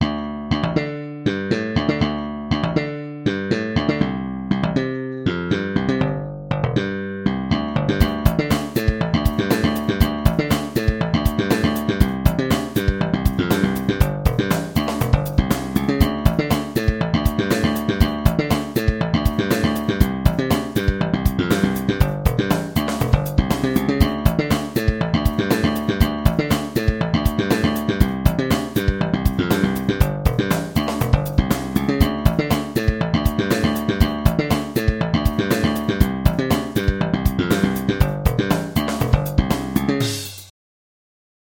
Bassesland KONTAKT is a virtual bass sample library in .nki format that emulates a wide range of sounds of electric, acoustic and synthesizer basses.
Slapped Electric Bass
Bassesland-12-Slapped-Electric-Bass.mp3